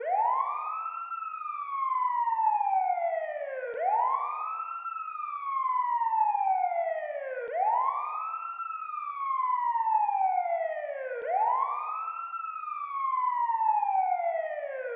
siren1.wav